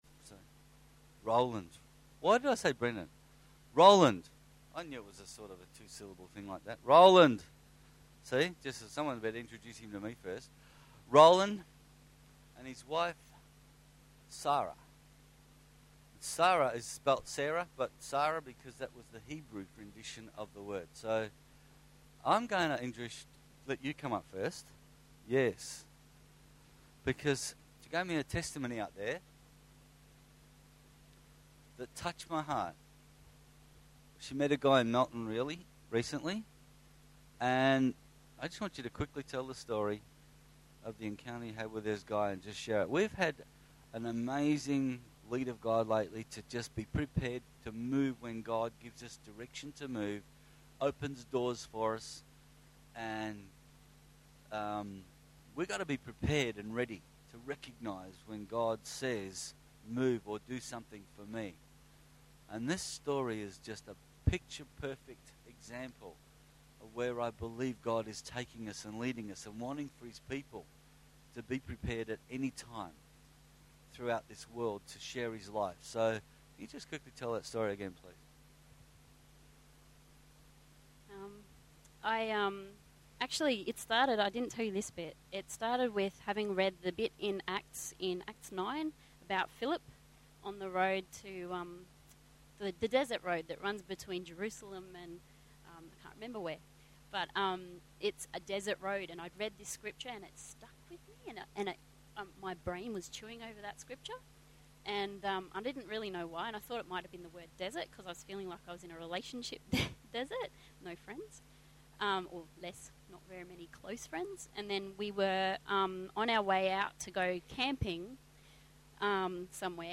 M.A.F Guest Speakers